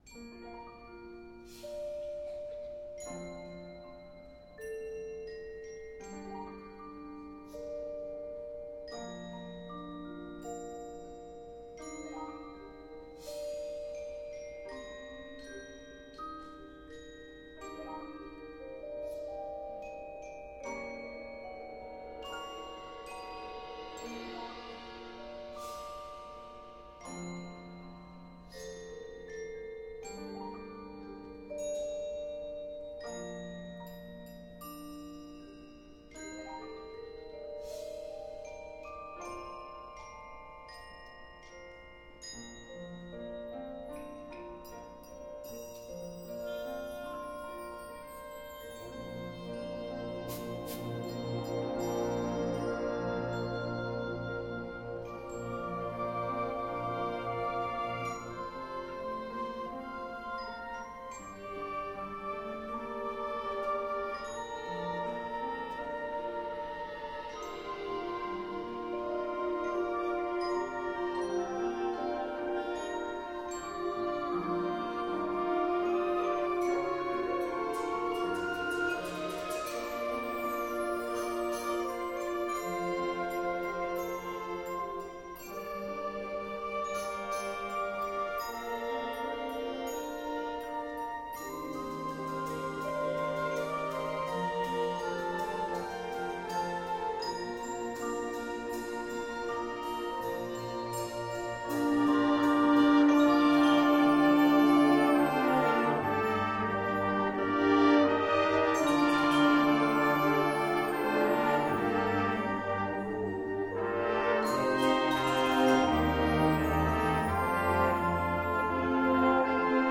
This composition is a quiet and moving work
ORIGINAL INSTRUMENTAL